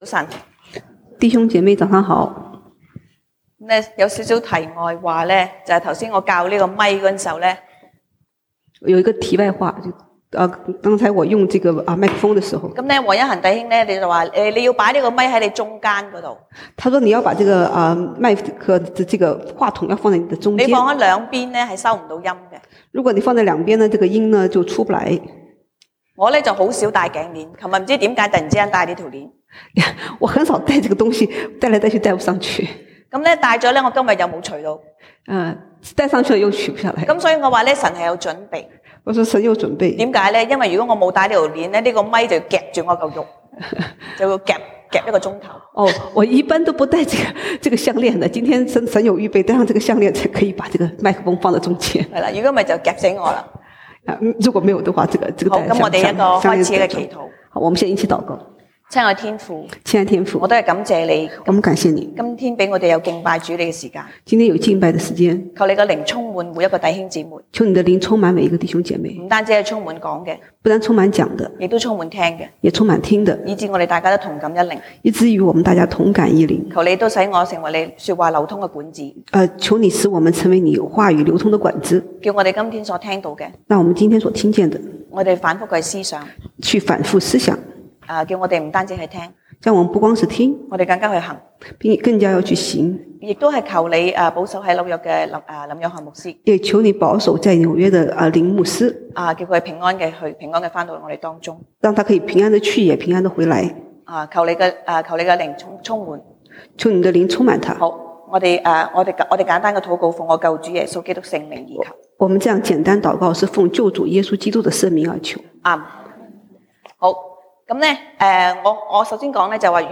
西堂證道(粵語/國語) Sunday Service Chinese: 箴言 3:1-35